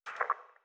splat_alt.wav